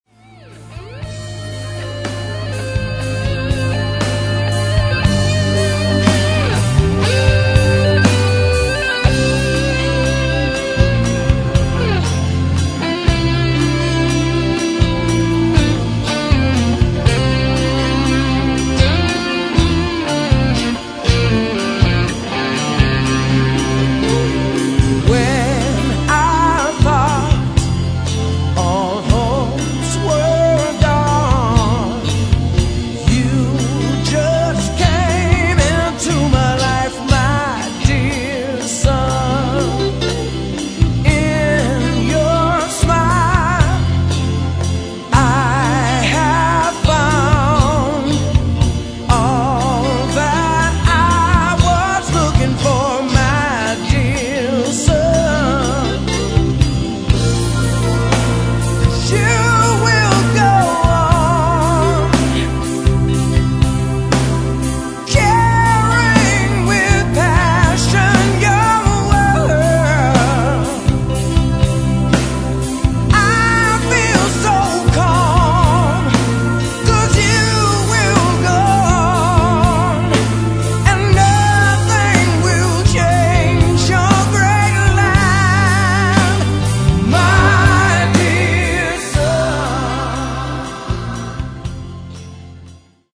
(low quality)